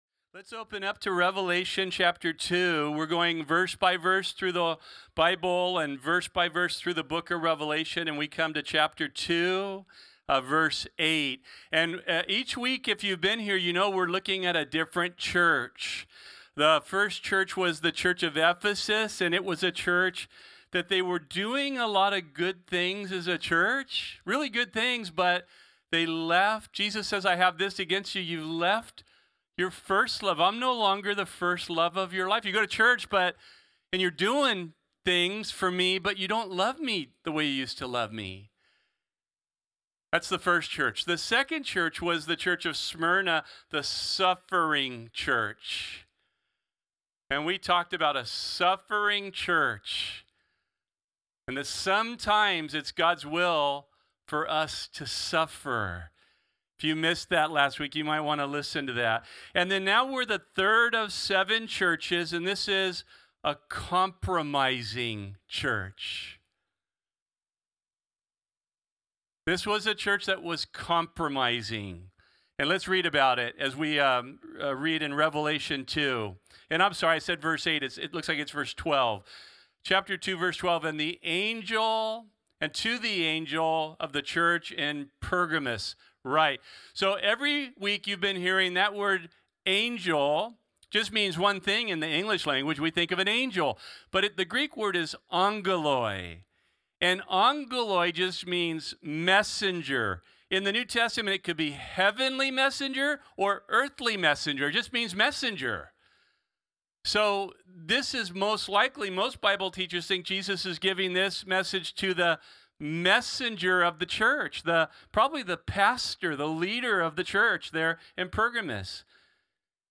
… continue reading 1 A Dead Church Play Pause 10d ago Play Pause Play later Play later Lists Like Liked — The Bible study given at Calvary Chapel Corvallis on Sunday, September 14, 2025.